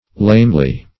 Lamely \Lame"ly\, adv. [See Lame.]